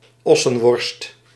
Summary Description Nl-ossenworst.ogg Dutch pronunciation for "ossenworst" — male voice.
Nl-ossenworst.ogg